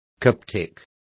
Complete with pronunciation of the translation of coptic.